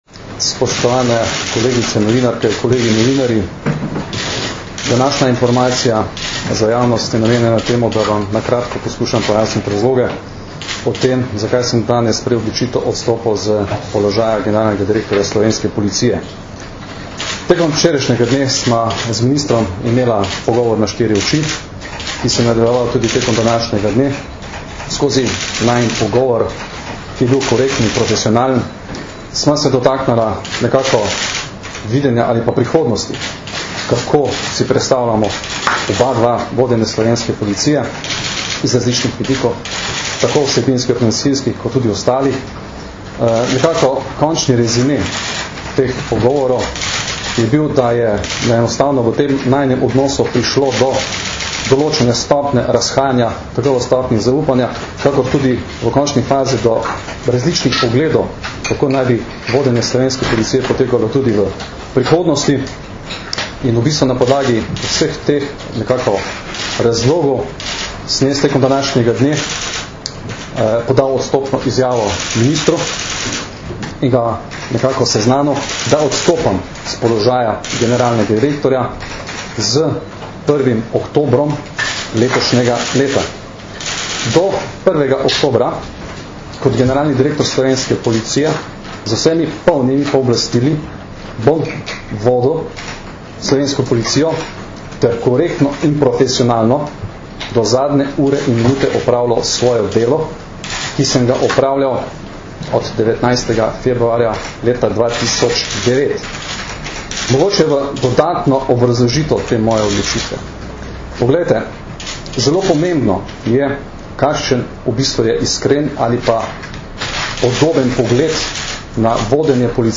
Zvočni posnetek izjave (mp3)